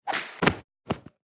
sfx_fall.opus